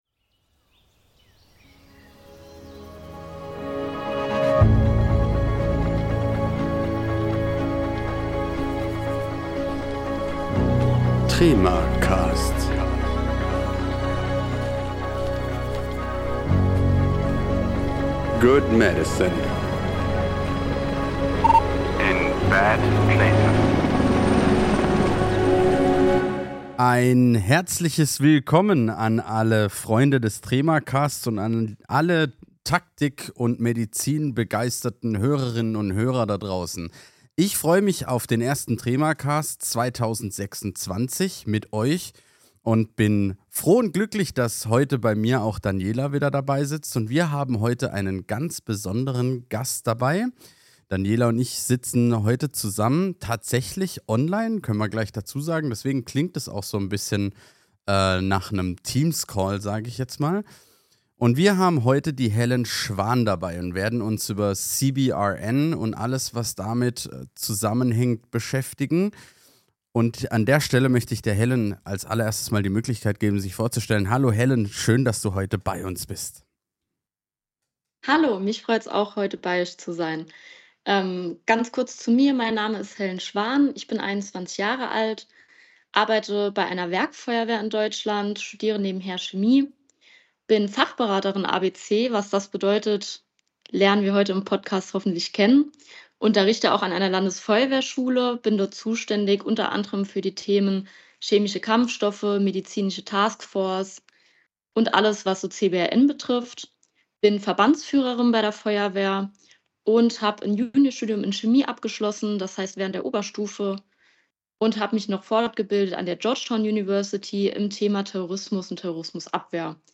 Rund 1,5 Stunden nehmen wir uns Zeit, um das Thema CBRN von allen Seiten zu beleuchten – mit einem besonderen Fokus auf die medizinischen Aspekte. Diese Folge wurde aus logistischen Gründen online aufgezeichnet – die Distanz zwischen unseren drei Gesprächspartnerinnen und -partnern wäre sonst schlicht zu groß gewesen. Die Qualität des Gesprächs hat das zum Glück nicht beeinträchtigt!